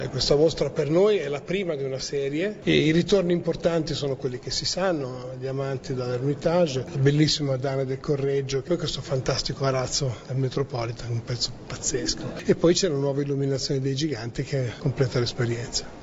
Al nostro microfono